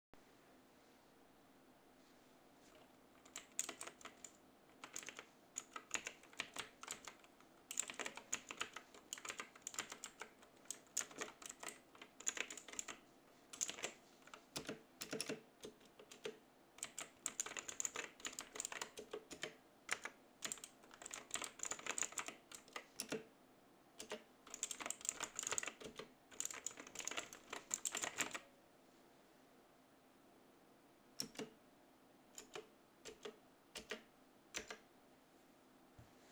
Kapcsoló: mechanikus, Outemu Blue
Hang: “click-tactile”, avagy kattogós
A hangja
Ennek köszönhetően kifejezetten kattanó hangja van a billentyűknek.
thor-300-hang.wav